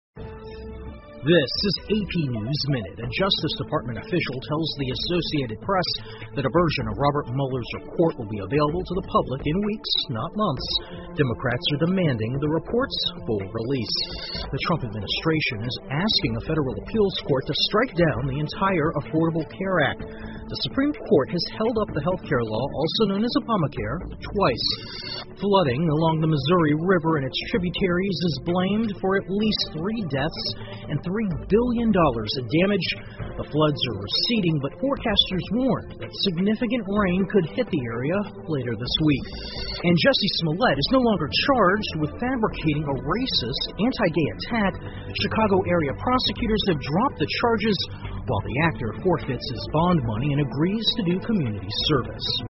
美联社新闻一分钟 AP 密苏里河洪水造成至少3人死亡 听力文件下载—在线英语听力室